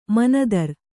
♪ manadar